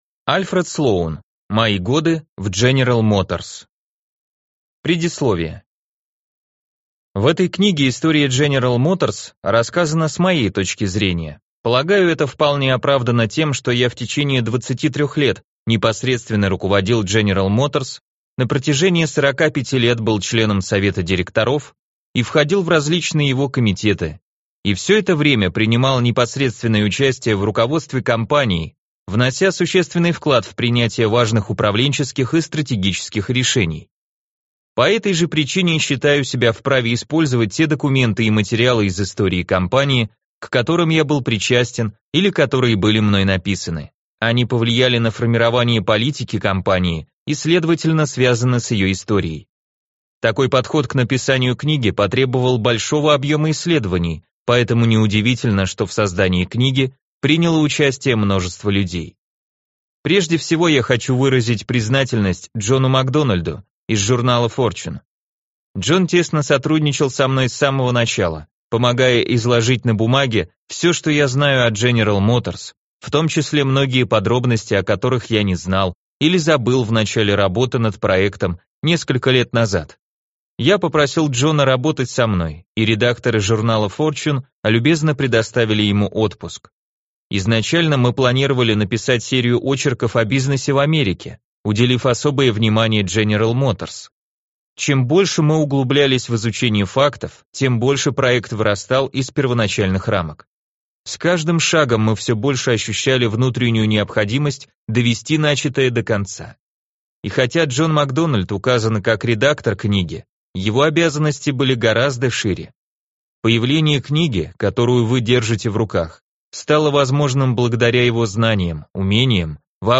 Аудиокнига Мои годы в General Motors. Часть 1 | Библиотека аудиокниг